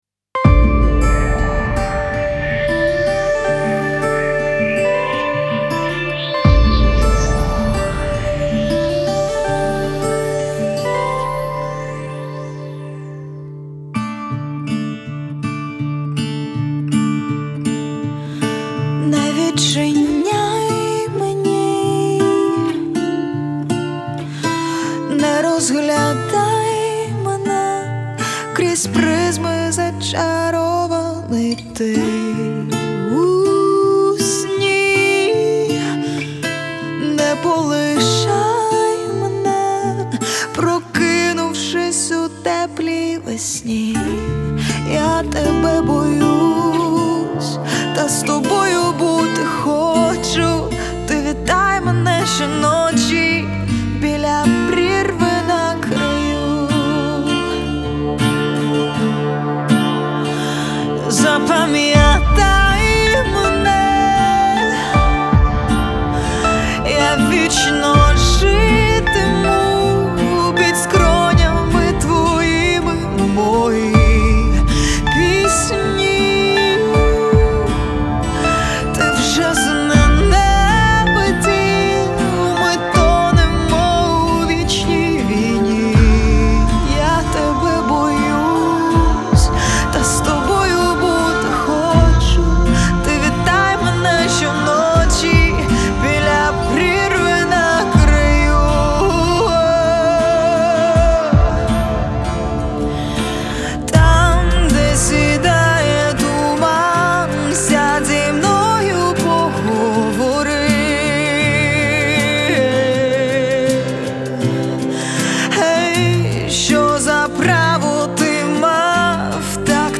Ukrainian, Kyiv rock band
В двух словах Одним словом про стиль - hard-поп.
progressive-pop.